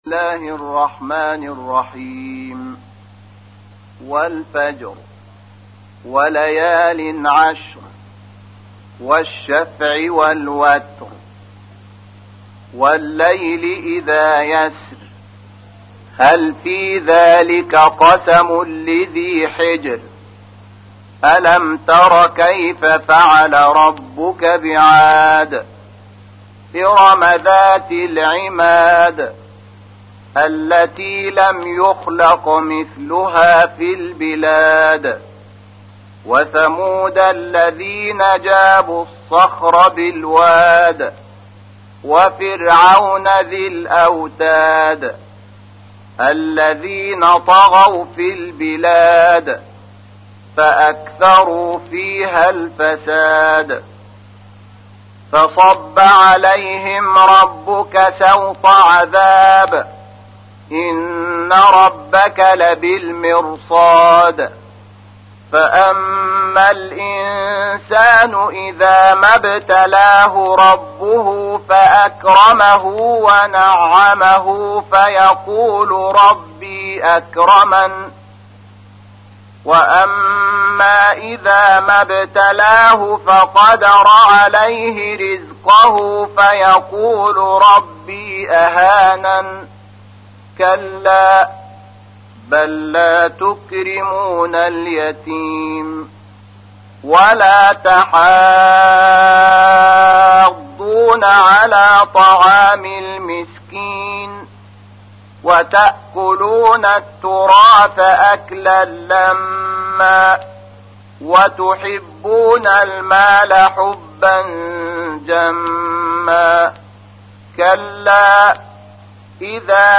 تحميل : 89. سورة الفجر / القارئ شحات محمد انور / القرآن الكريم / موقع يا حسين